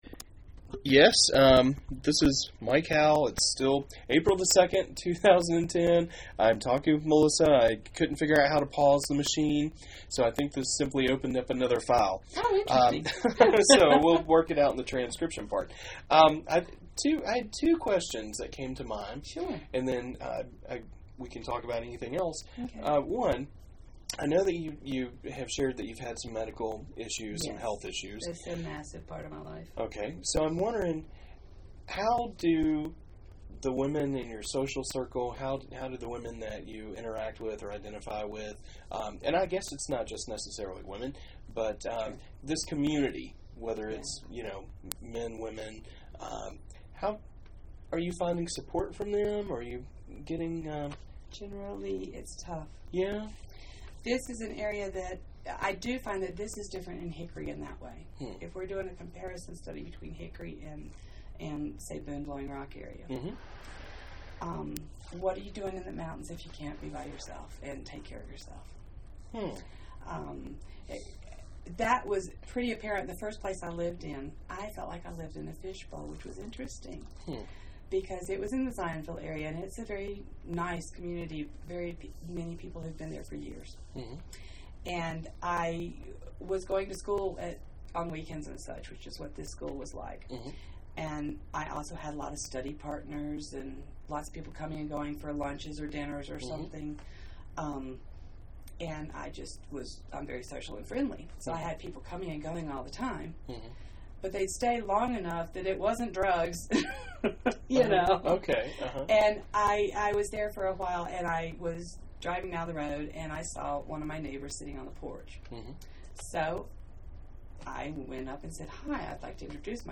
Appalachian Lesbian, Gay, Bisexual, and Transgender Oral History Project